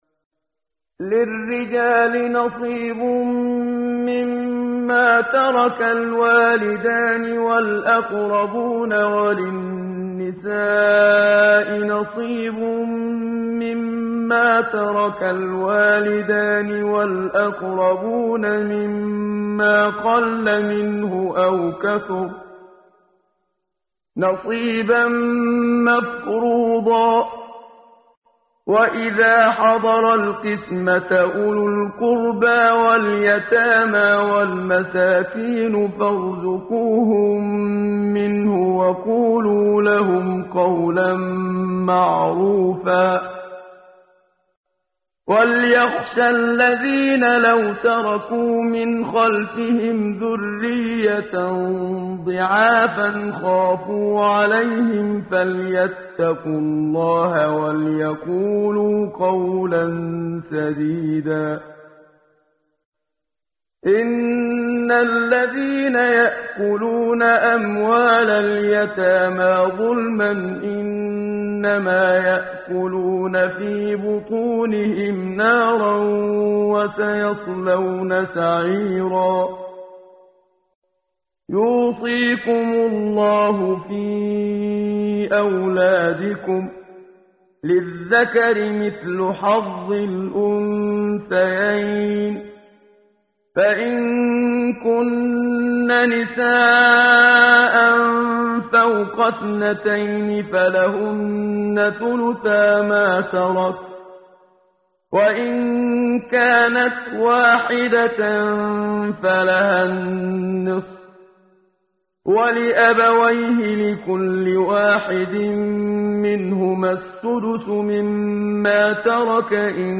ترتیل صفحه 78 سوره مبارکه سوره نساء (جزء چهارم) از سری مجموعه صفحه ای از نور با صدای استاد محمد صدیق منشاوی